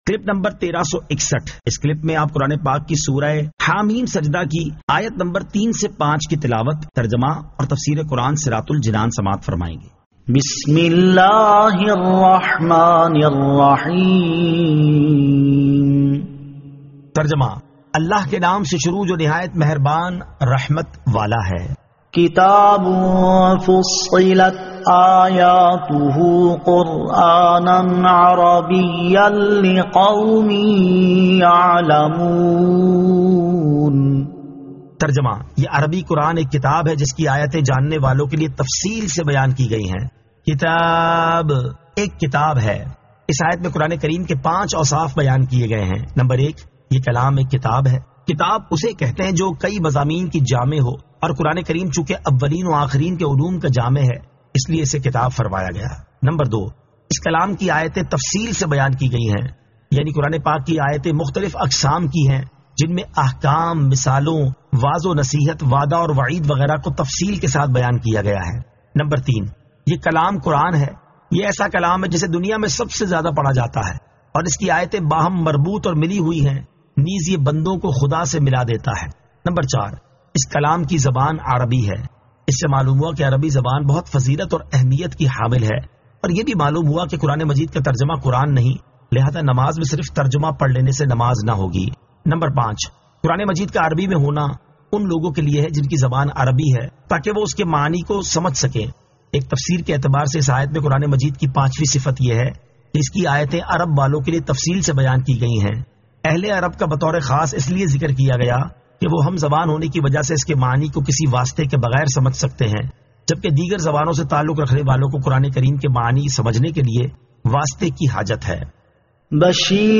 Surah Ha-Meem As-Sajdah 03 To 05 Tilawat , Tarjama , Tafseer